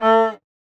animalia_cow_hurt.ogg